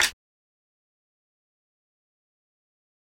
Perc 1.wav